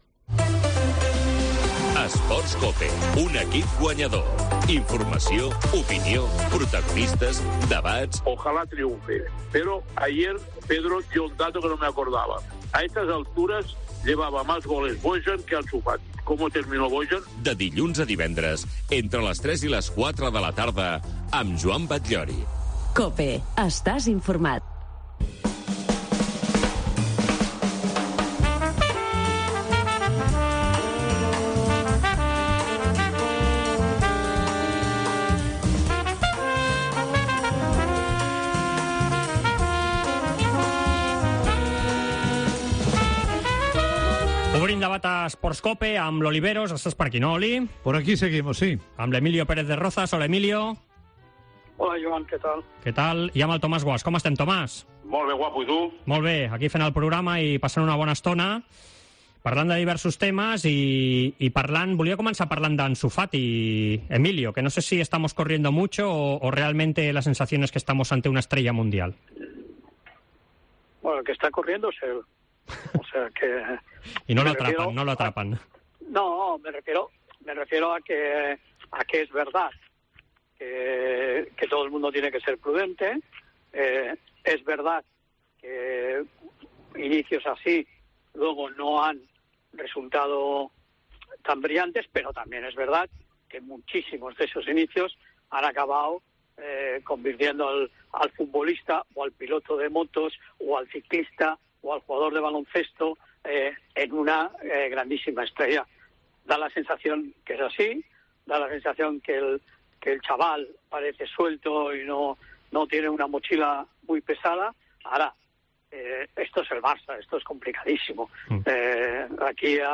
El debat Esports COPE de dimarts